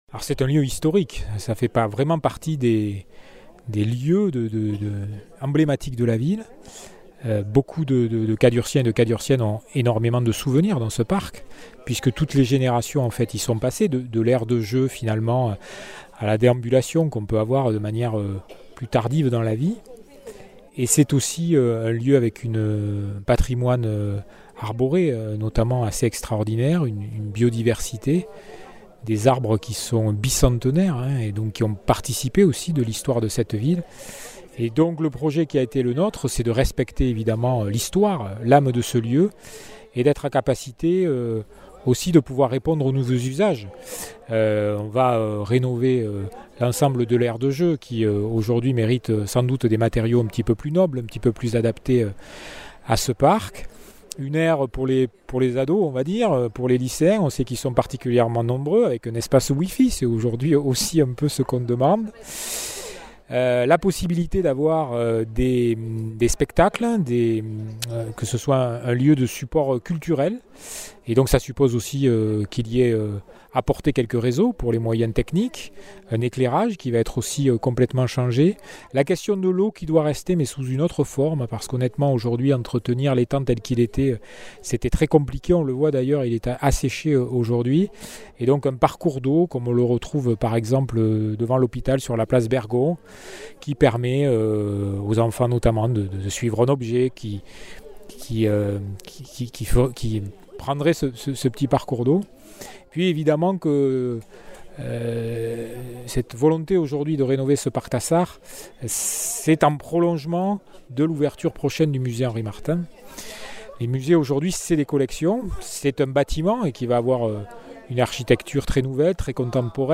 Interviews
Invité(s) : Jean Marc Vayssouze Faure, Maire de Cahors